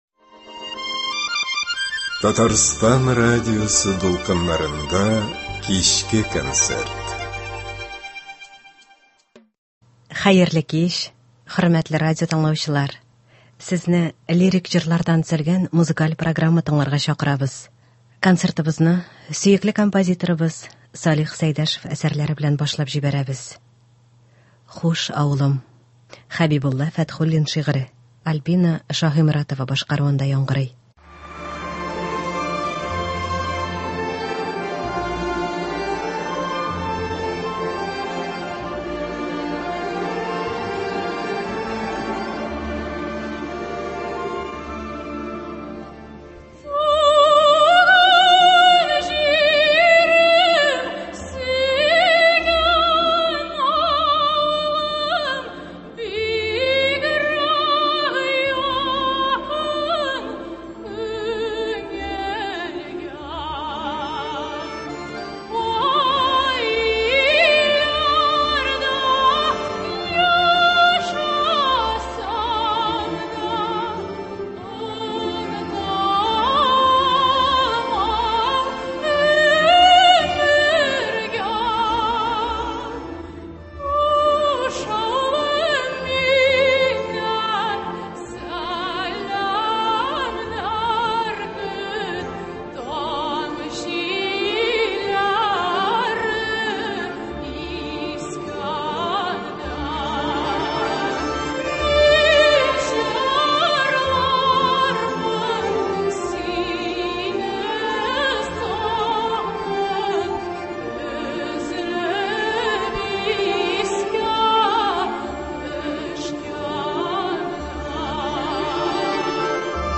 Лирик концерт.